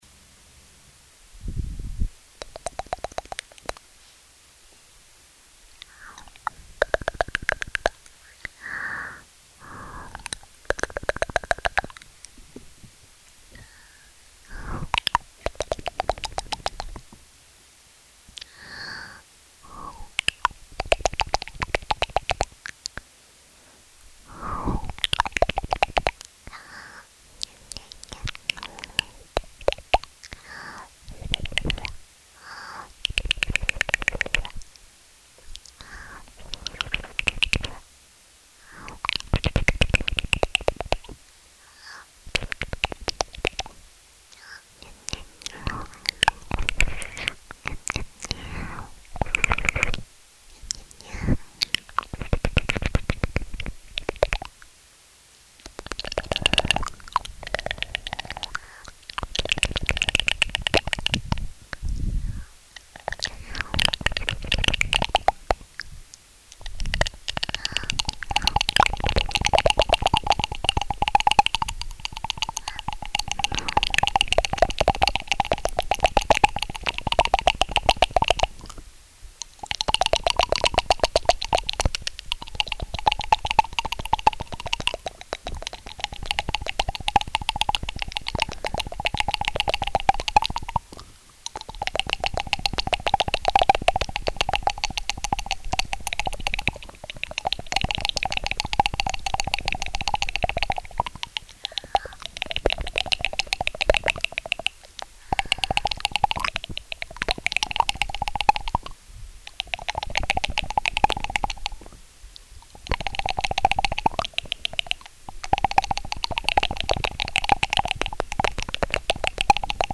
ASMR在线